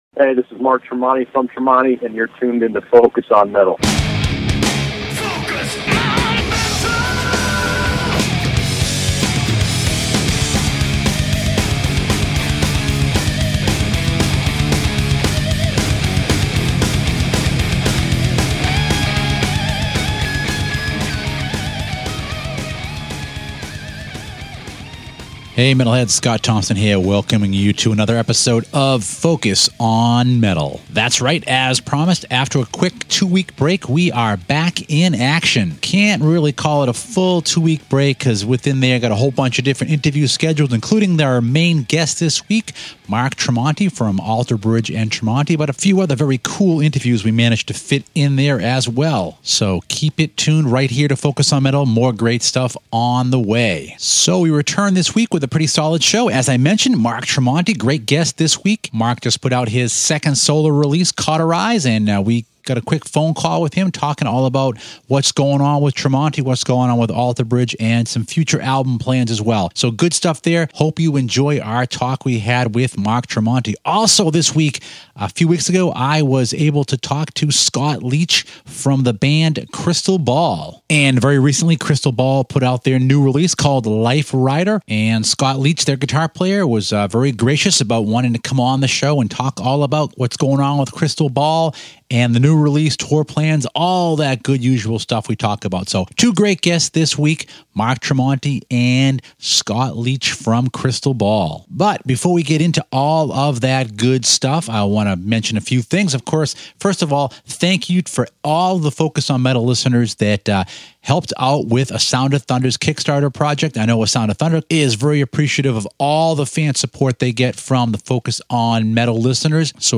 Episode 247 - Tremonti We have two guests on tap this week.